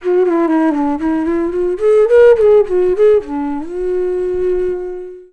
FLUTE-B09 -R.wav